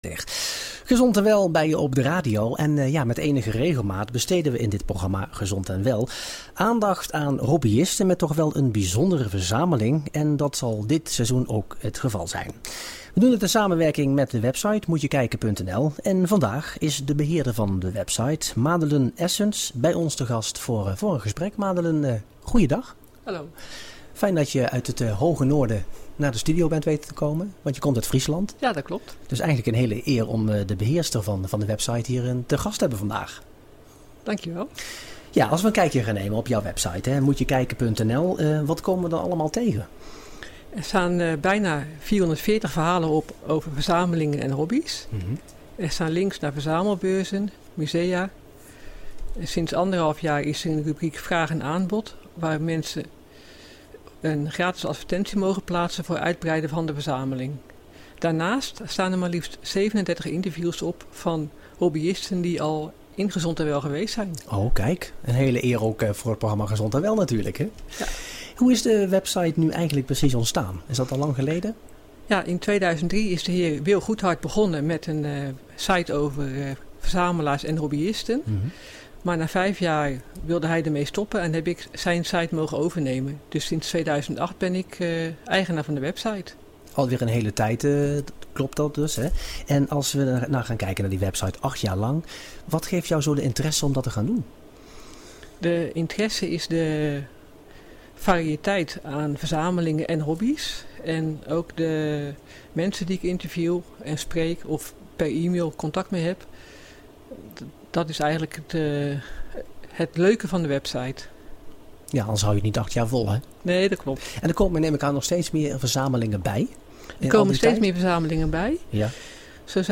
Interviews met verzamelaars en hobbyisten
Interview met verzamelaar/hobbyist in radiouitzendingen "Gezond en wel":
in de studio bij 'Gezond en Wel' op 18 september 2016